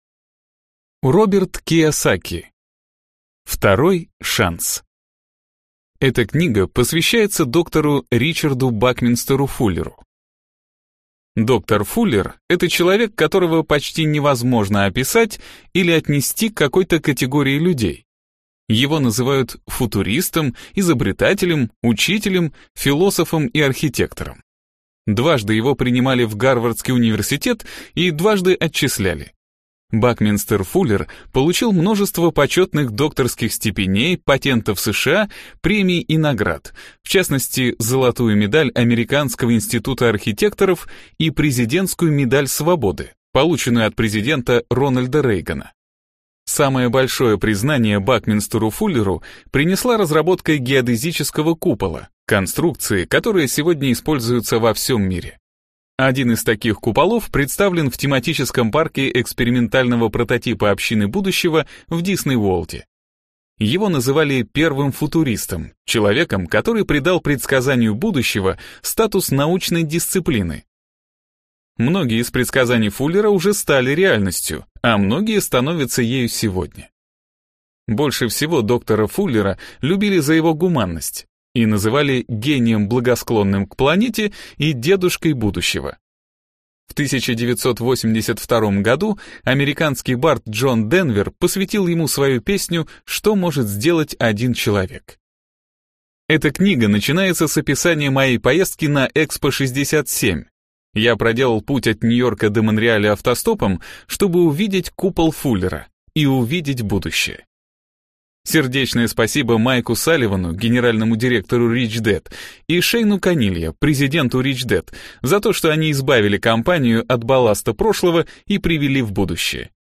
Аудиокнига Второй шанс | Библиотека аудиокниг
Прослушать и бесплатно скачать фрагмент аудиокниги